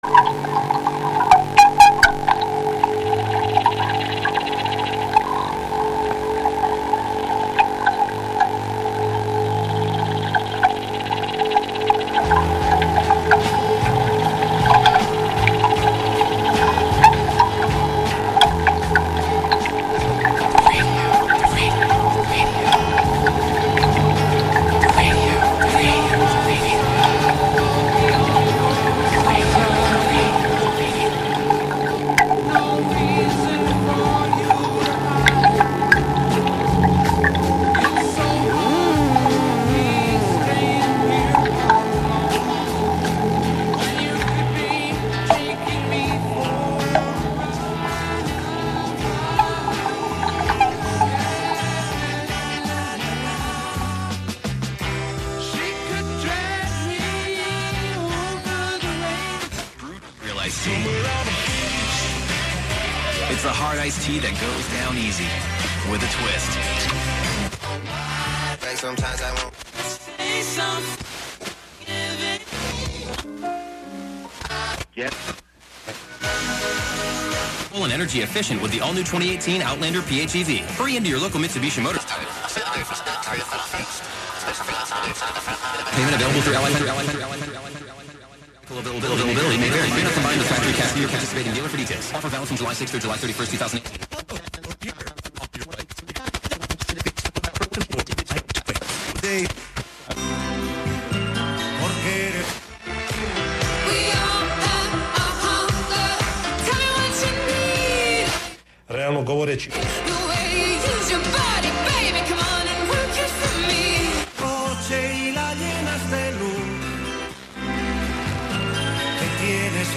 Live from Brooklyn, NY
turns the very bits and bytes of commercial culture into the driving backbeat to our dance of independence. Radio Wonderland abstracts live FM radio with laptop, electrified shoes hit with sticks, and a computer-hacked steering wheel (from a Buick 6).
deploy slices of radio on a rhythmic grid making instant techno 90% of the time
extract the sibilance, play those S's, T's and K's like a drum machine
morph slowed-down radio into a bass drum to shake the dance floor